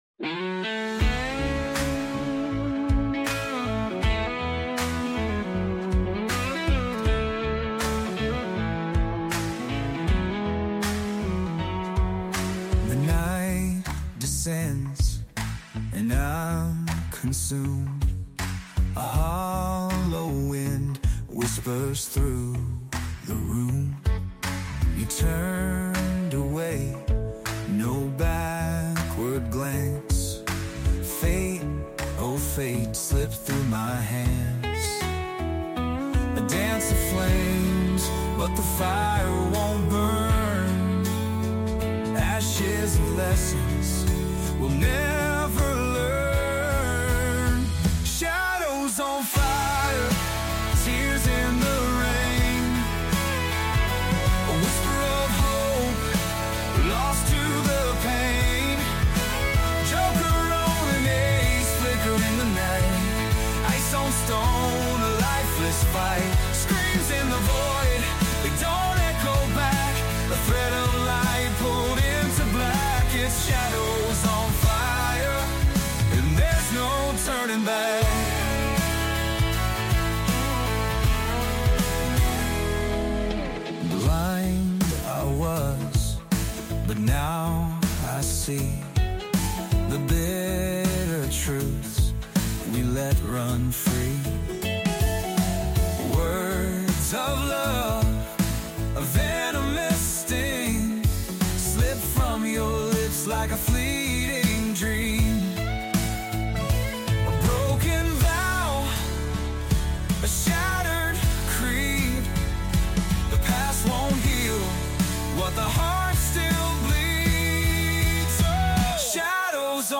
a heartfelt country tribute